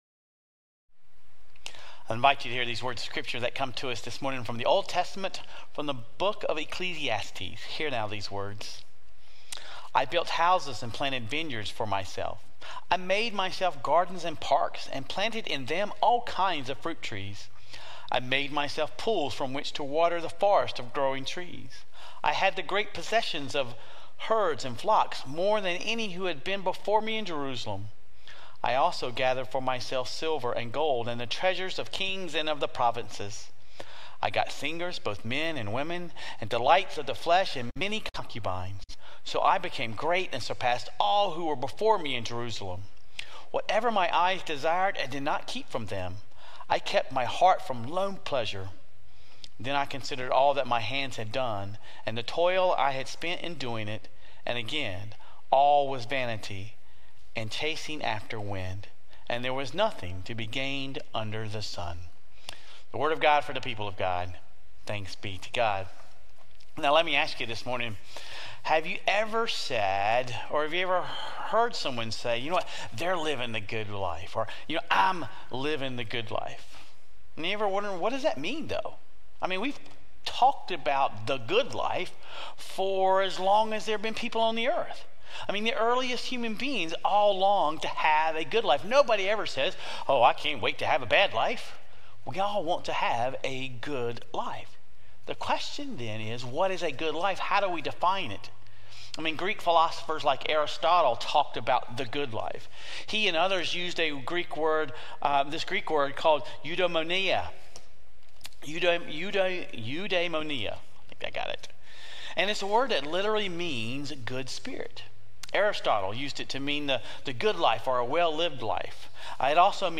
We’ll explore ways we can reframe our lives, our money, and the ways we think about success. Sermon Reflections: In what ways did the sermon challenge your ideas about material possessions and wealth being the keys to a fulfilling life?